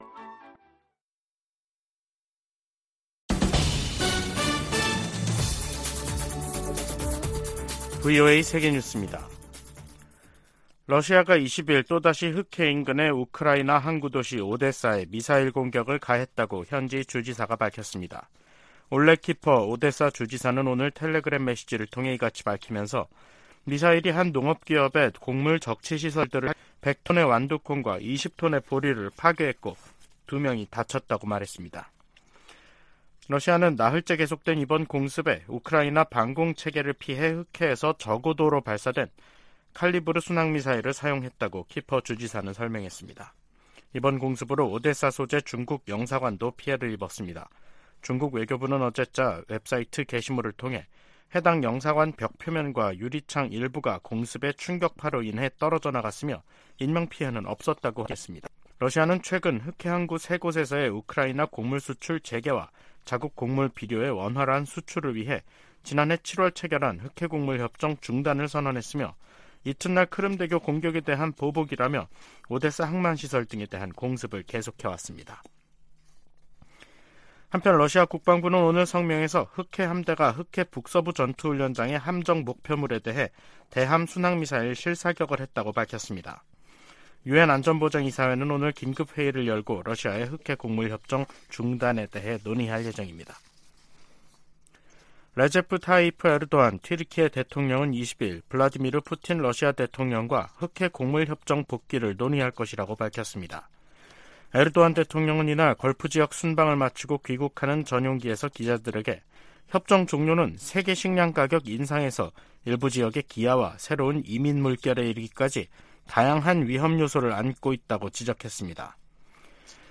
VOA 한국어 간판 뉴스 프로그램 '뉴스 투데이', 2023년 7월 21일 3부 방송입니다. 백악관은 월북 미군 병사의 안전과 소재 파악에 최선을 다하고 있지만 현재 발표할 만한 정보는 없다고 밝혔습니다. 미국과 한국의 핵협의그룹(NCG)을 외교・국방 장관 참여 회의체로 격상하는 방안이 미 상원에서 추진되고 있습니다. 미 국방부가 전략핵잠수함(SSBN)의 한국 기항을 비난하며 핵무기 사용 가능성을 언급한 북한의 위협을 일축했습니다.